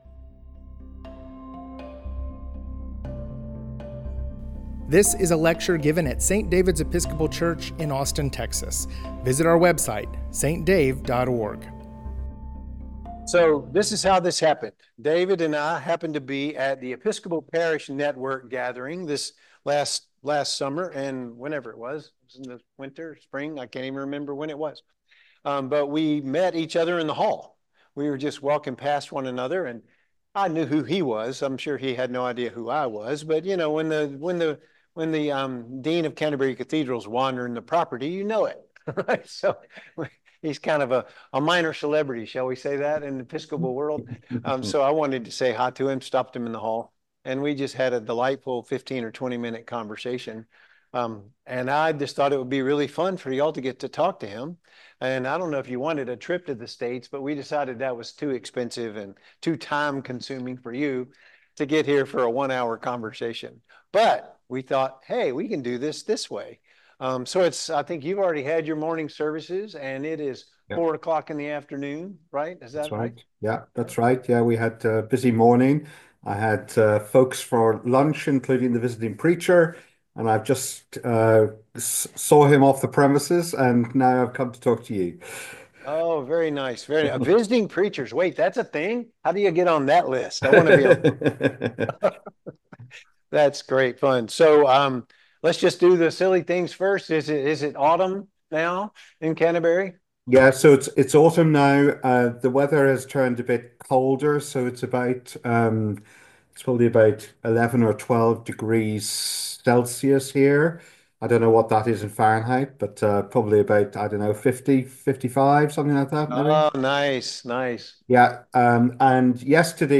joined us via Zoom for a Holy Conversation